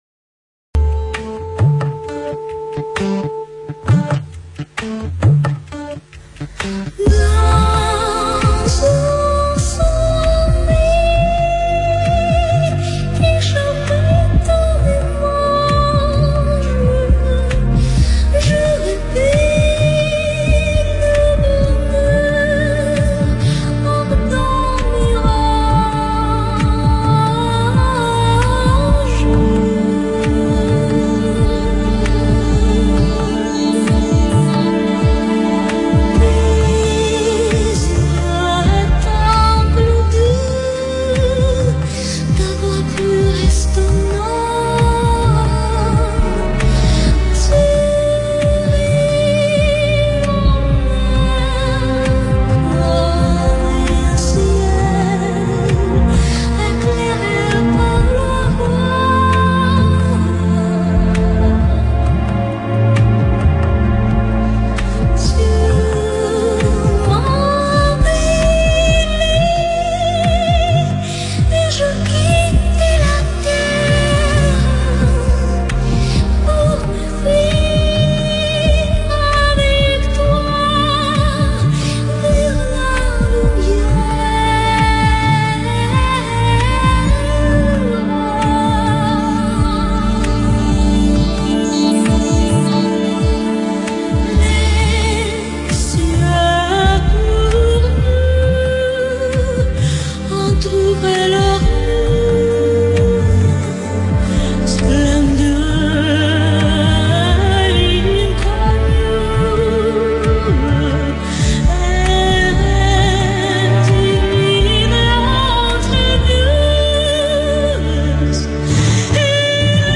Кто исполняет джазовую обработку "Apres un reve"?